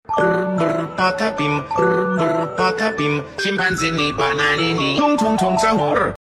br br patapim tung tung sahur alarm sound.